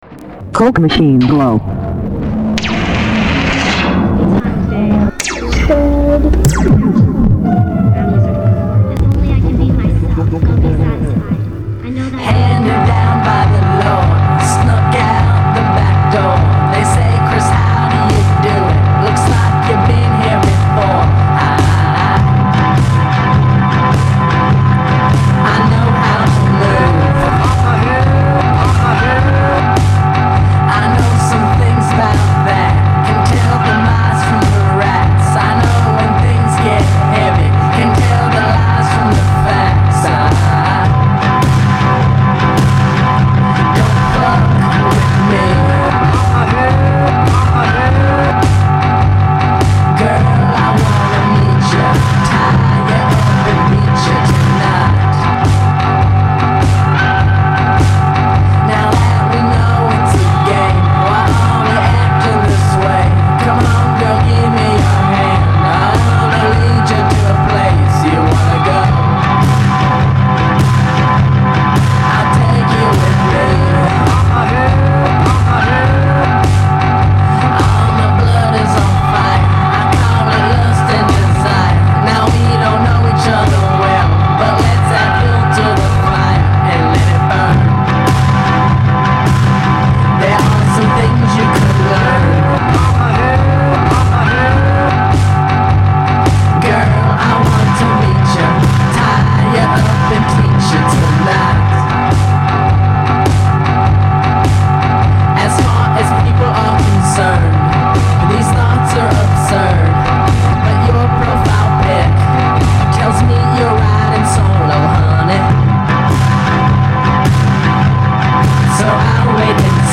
A microphone in a glass of water and icecubes!